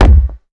ball-wall-hit.ogg